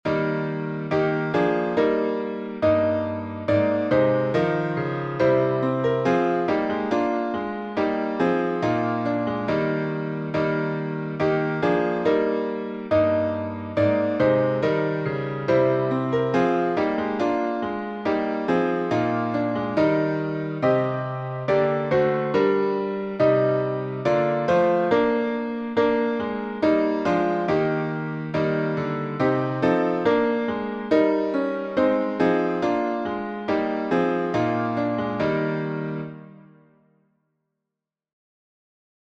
Key signature: E flat major (3 flats) Time signature: 3/2